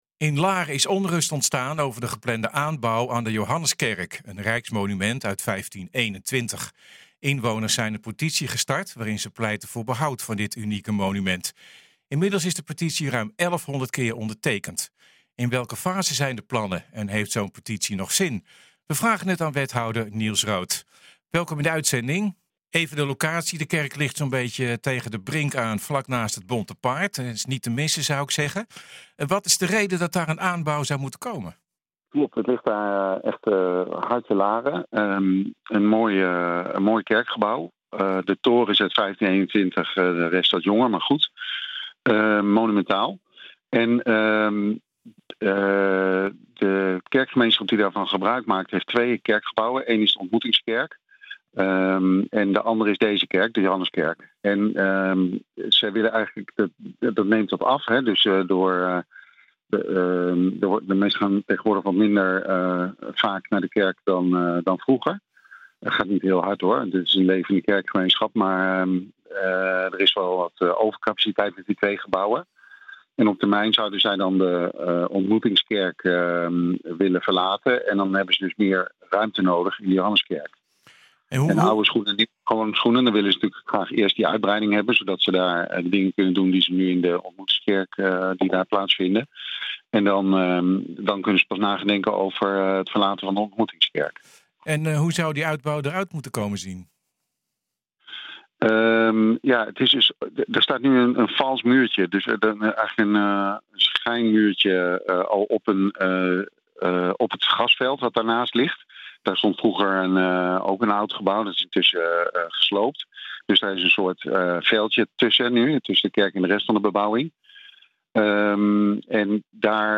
We vragen het aan wethouder Niels Rood.